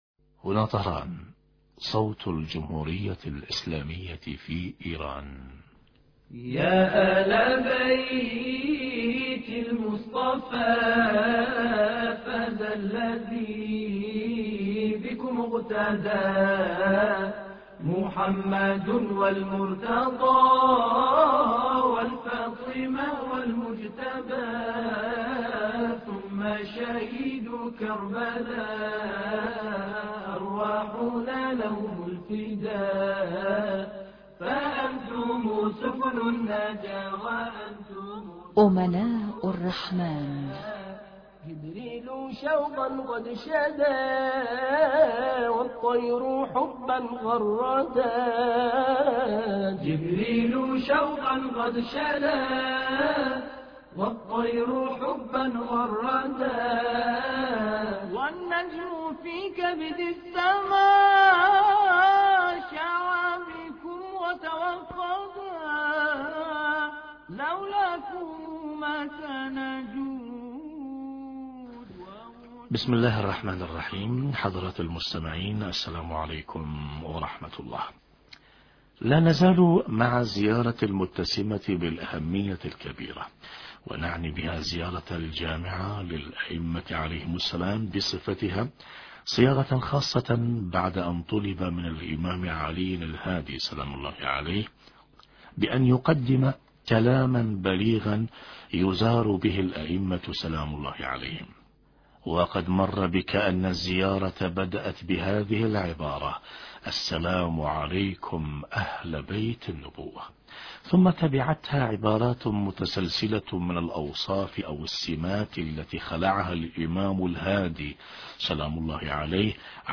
مقال في شرح قوله (عليهم السلام): (ذوي النهى وأولي الحجى) حوار
أما الآن نتابع تقديم برنامج امناء الرحمن بهذا الاتصال الهاتفي